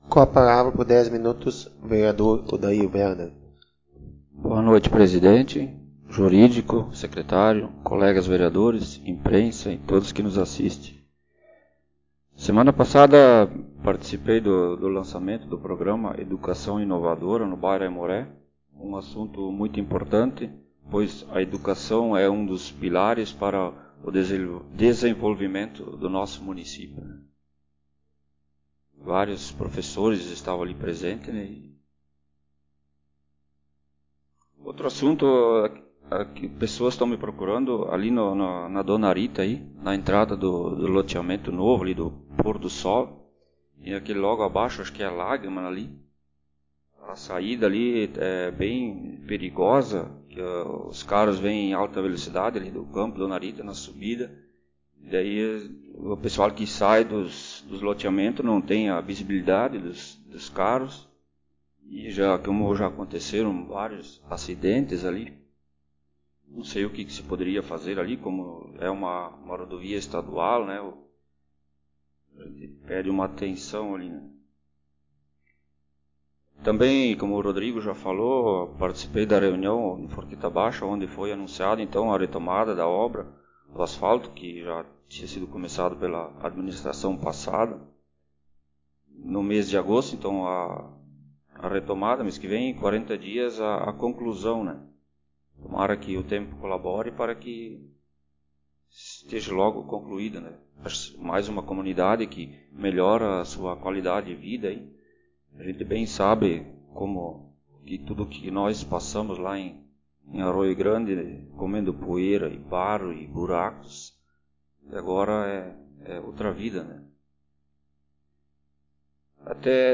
Áudio das Sessões Vereadores